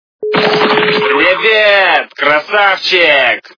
» Звуки » Смешные » Превед - красавчег
При прослушивании Превед - красавчег качество понижено и присутствуют гудки.